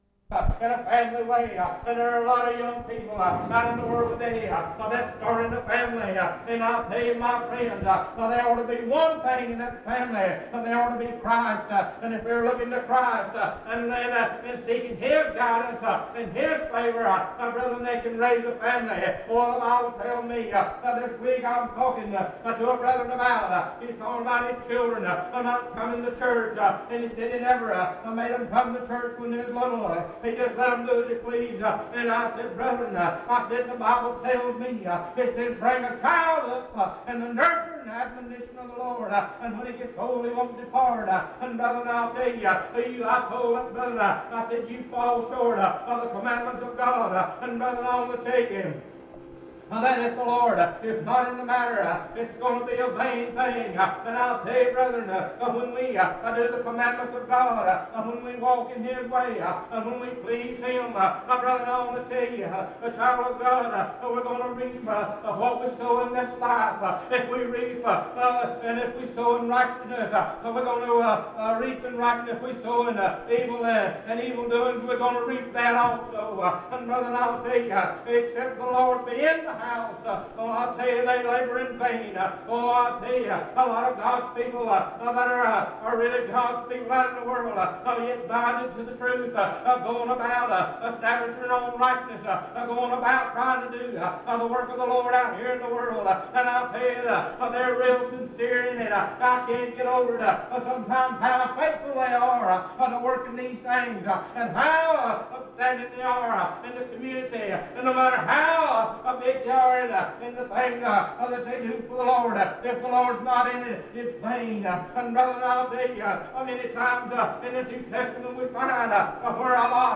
In Collection: Primitive Baptist churches audio recordings Thumbnail Title Date Uploaded Visibility Actions PBHLA-ACC.004_014-A-01.wav 2026-02-12 Download PBHLA-ACC.004_014-B-01.wav 2026-02-12 Download